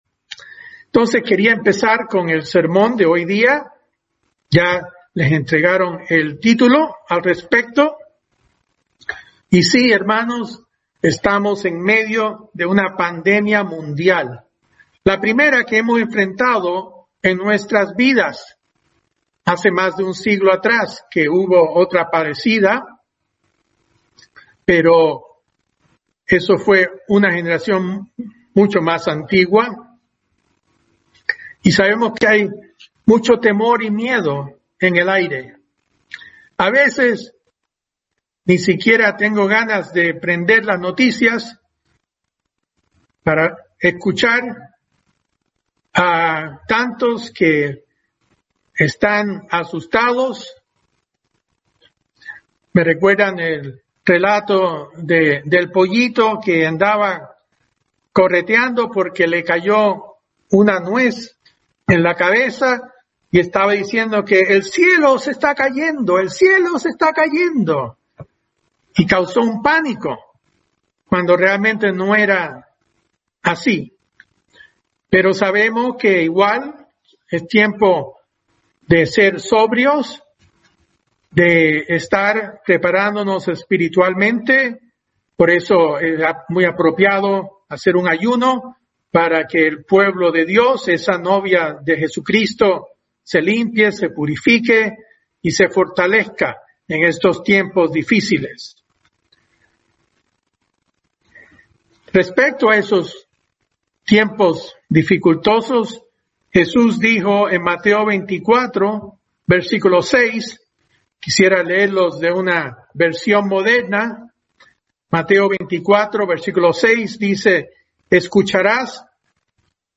La mejor forma de hacerlo, es avivar el Espíritu Santo de Dios en nosotros, que nos da el valor y el amor para ser luces al mundo. Mensaje entregado el 25 de abril de 2020.